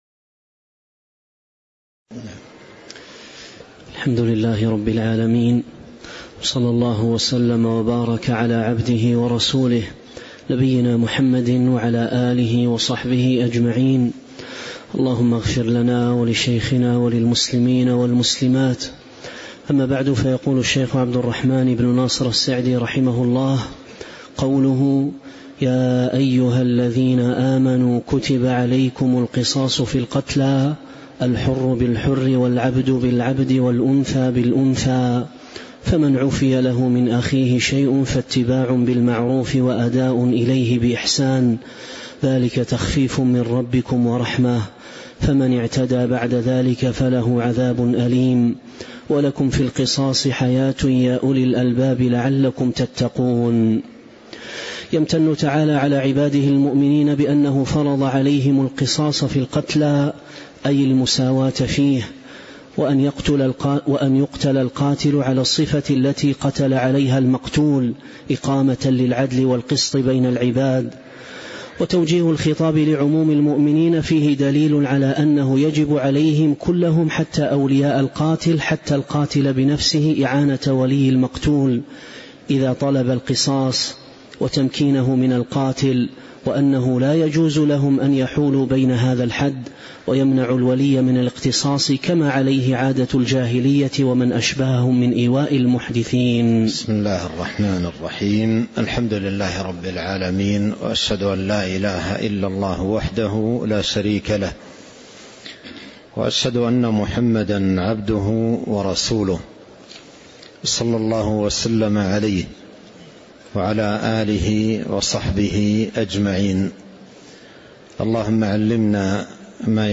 تاريخ النشر ١٢ رجب ١٤٤٦ هـ المكان: المسجد النبوي الشيخ